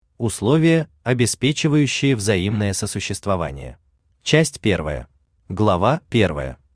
Aудиокнига Модус Вивенди Автор Сергей Анатольевич Галенко Читает аудиокнигу Авточтец ЛитРес.